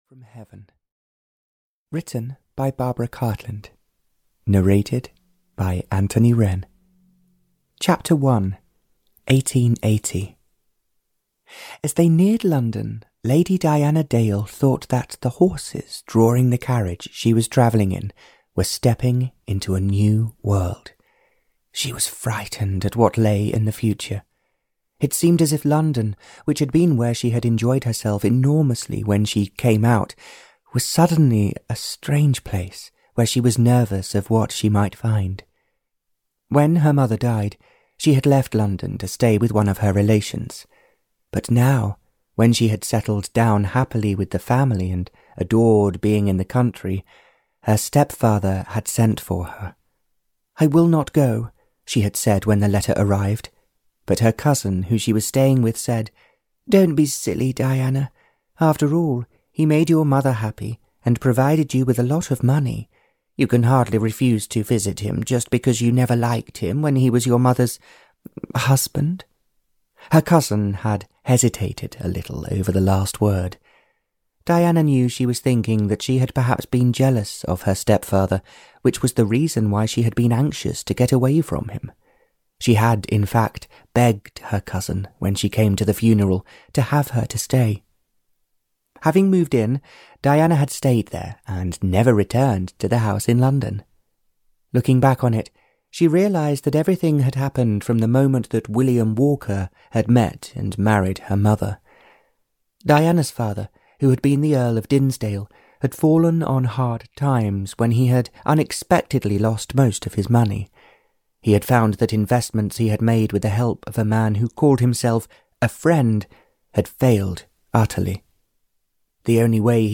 Audio knihaAn Angel from Heaven (Barbara Cartland's Pink Collection 141) (EN)
Ukázka z knihy